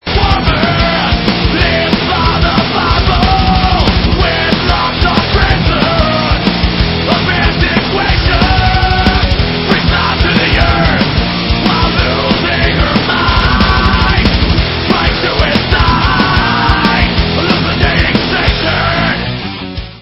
Rock - Speed/Thrash/Death Metal